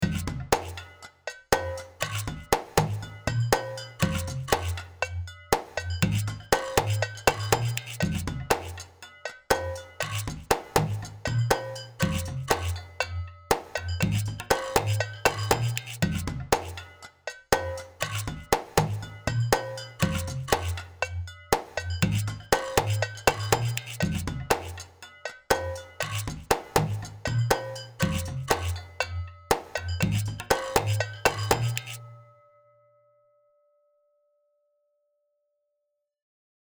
Hidden Objects Percussion features percussive hits and loops of all sorts of real world sonic treasures, played with various articulations, featuring percussive hits and loops of sonic treasures found in the real world.
Hidden Percussion 2
(the demos contain ONLY sounds in the actual library)